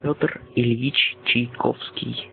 In Cyrillic script, his name is written as Пётр Ильич Чайковский (Петръ Ильичъ Чайковскій in Russian pre-revolutionary script), IPA: [ˈpʲɵtr ɨˈlʲjitɕ tɕɪjˈkofskʲɪj]
Ru-Pyotr_Ilyich_Tchaikovsky.ogg.mp3